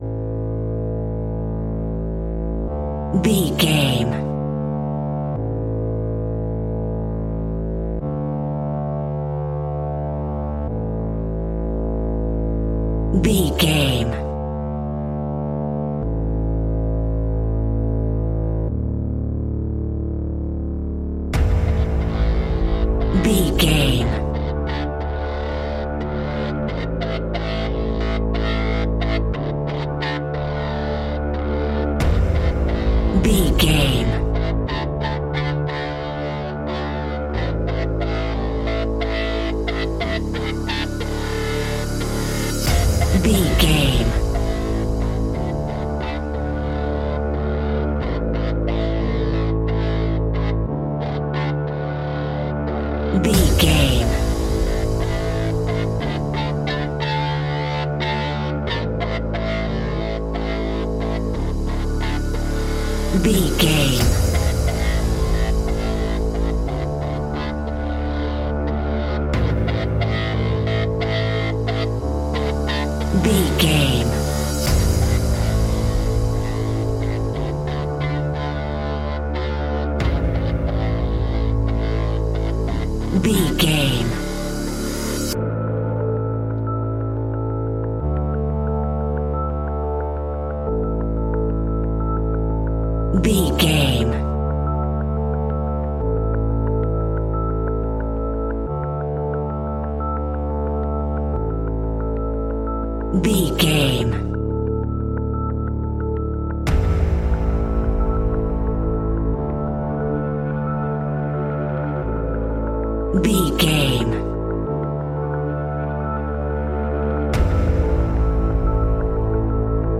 Aeolian/Minor
D
scary
ominous
dark
haunting
eerie
synthesiser
drums
electronic music
electronic instrumentals